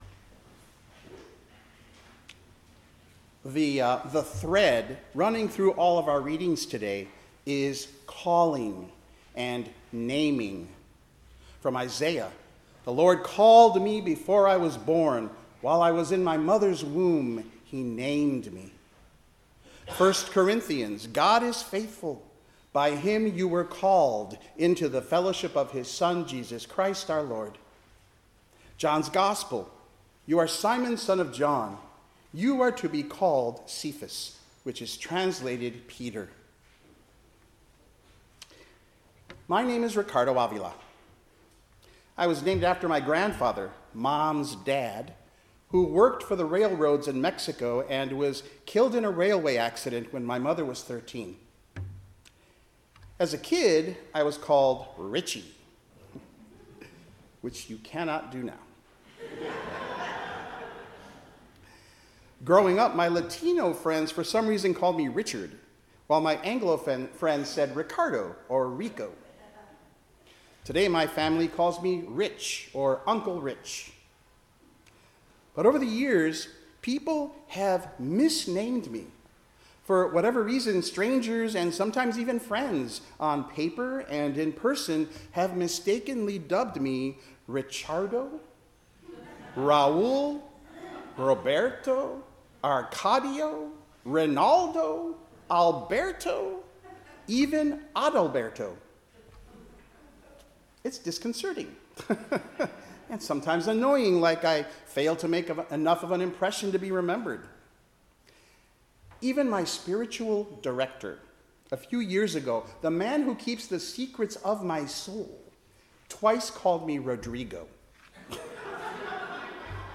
Passage: Isaiah 49:1-7, Psalm 40:1-12, 1 Corinthians 1:1-9, John 1:29-42 Service Type: 10:00 am Service